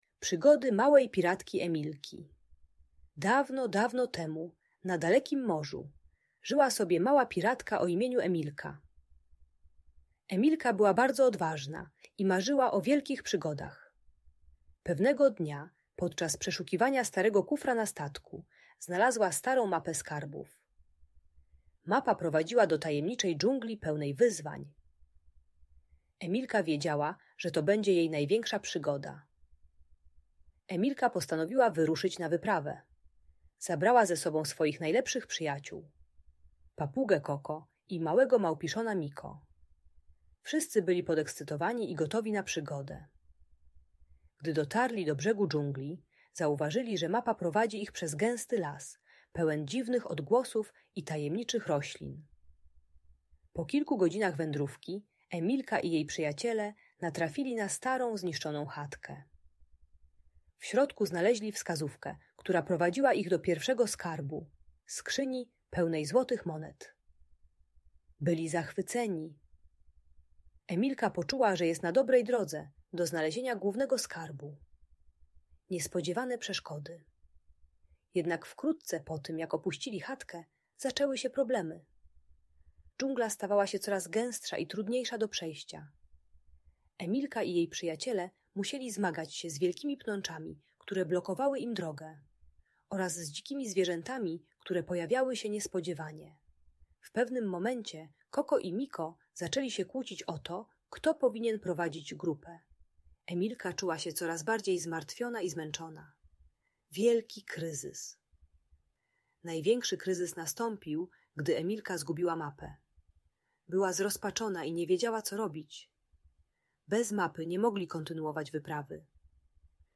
Przygody Małej Piratki Emilki - Opowieść pełna przygód - Audiobajka